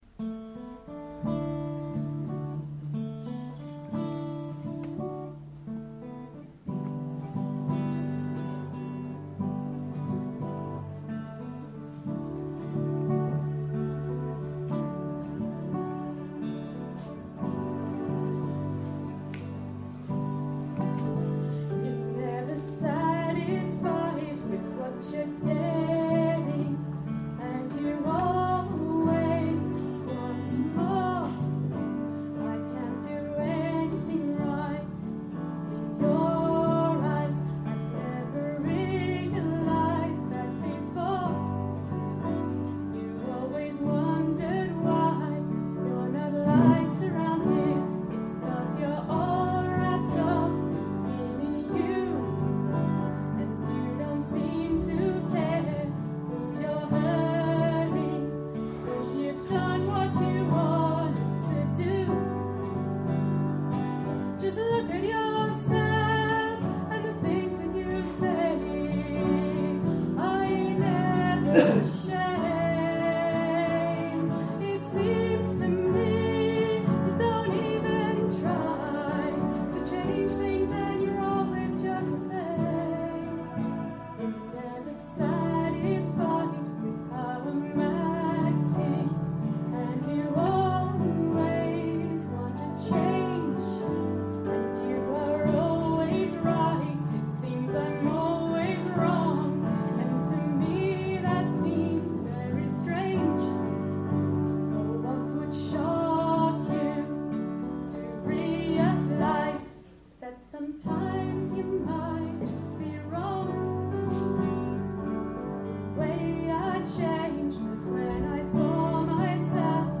Never Satisfied, with me on Vocal and guitar
keyboard